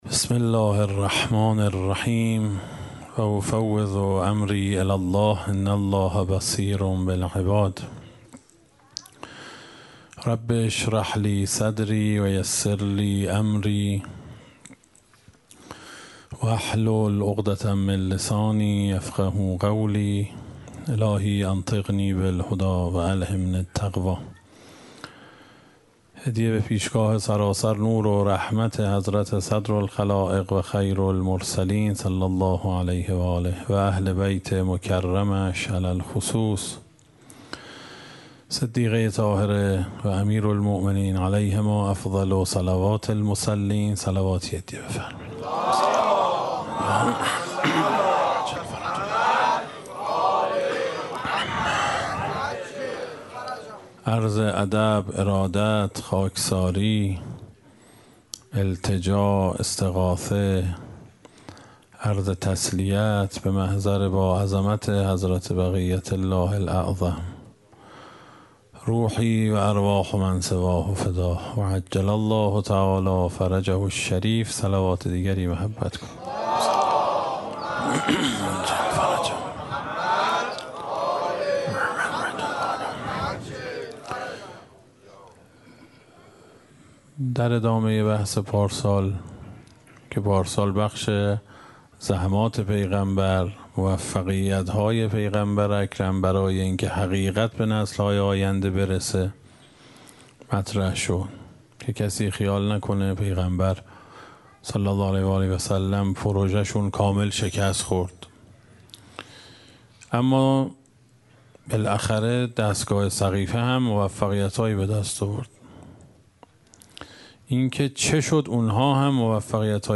اشتراک گذاری دسته: الهیات شکست , حضرت فاطمه سلام الله علیها , سخنرانی ها قبلی قبلی الهیات شکست؛ فاطمیه اول – جلسه پنجم از ده جلسه بعدی الهیات شکست؛ فاطمیه اول – جلسه هفتم از ده جلسه بعدی